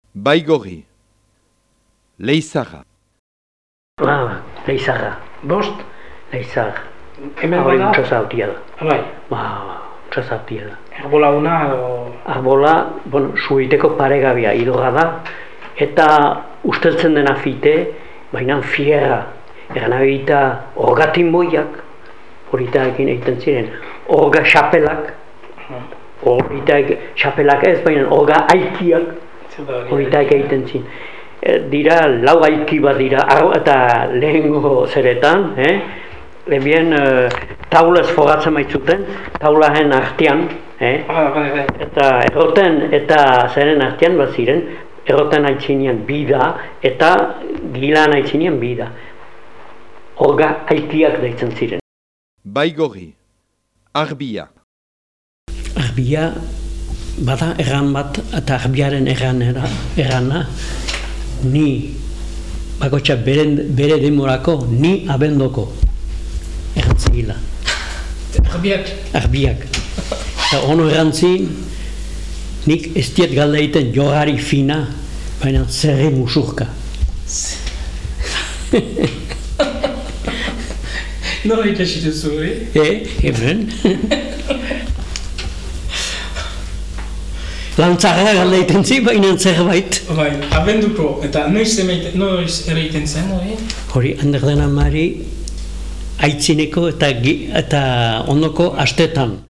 6.3. BAIGORRI
Baigorri.mp3